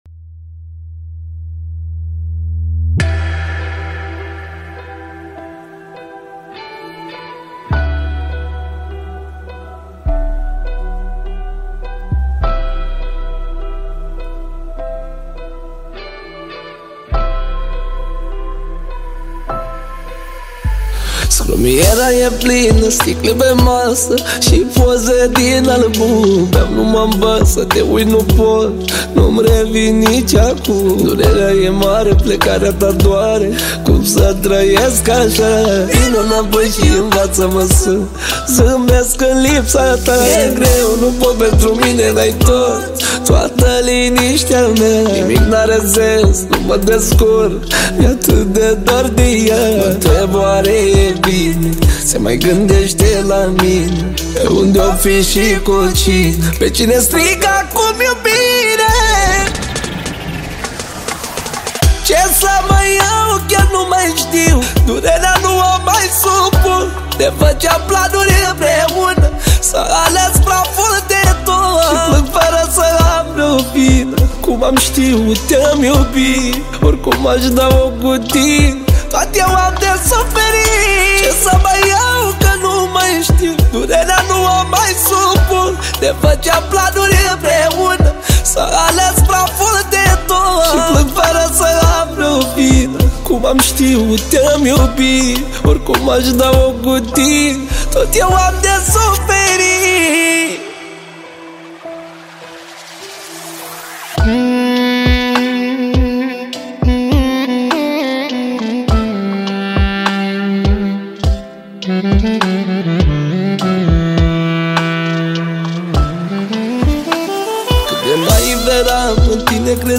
Data: 10.10.2024  Manele New-Live Hits: 0